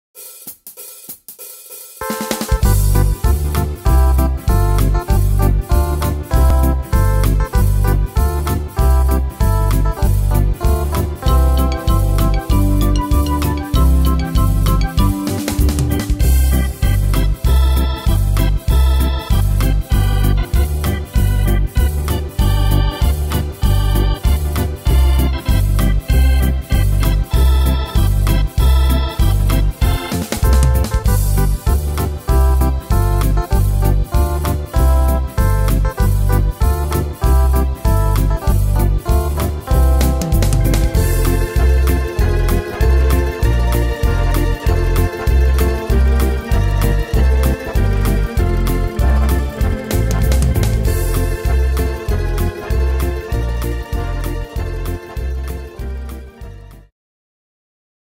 Tempo: 194 / Tonart: C-Dur